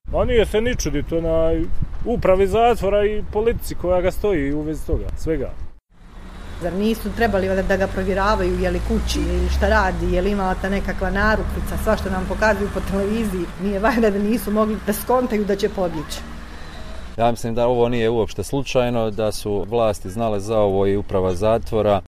Građani, ako je suditi po izjavama onih koje smo anketirali, za cijeli slučaj okrivljuju nadležne: